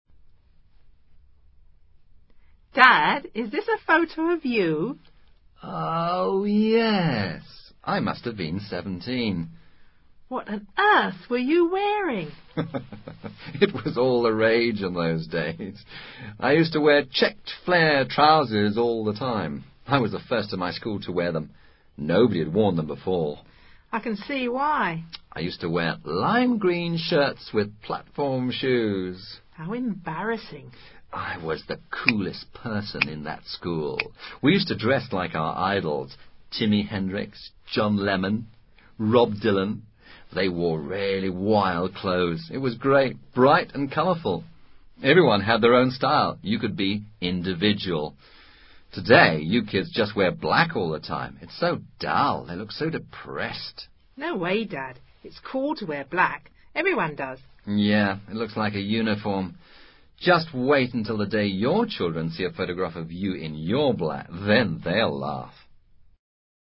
Conversación entre un padre y su hija en la que se presentan palabras relacionadas con la moda y el vestuario.
El archivo de audio adjunto ofrece una conversación entre un padre y su hija en la que discuten acerca de la moda en sus respectivas etapas escolares. Los principales contenidos de Inglés abordados en este diálogo son: must have been, used to, past perfect simple, clothing vocabulary.